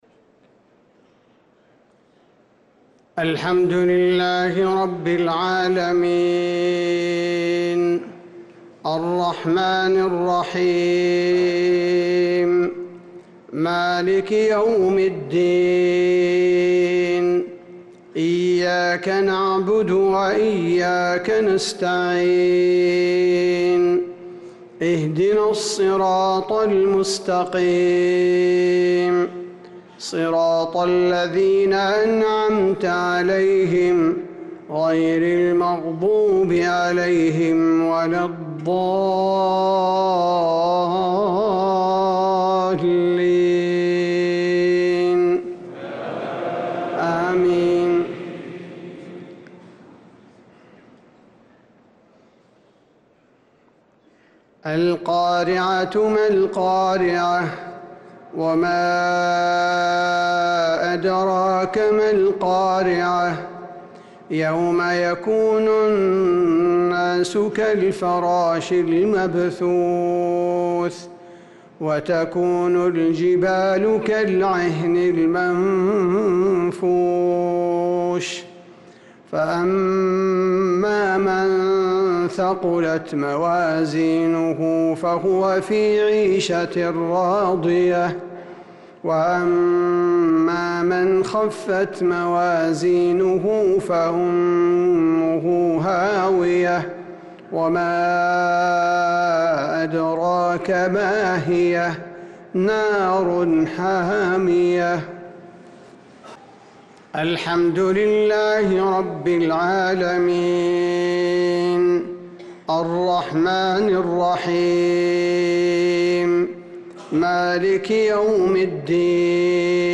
صلاة المغرب للقارئ عبدالباري الثبيتي 19 شوال 1445 هـ
تِلَاوَات الْحَرَمَيْن .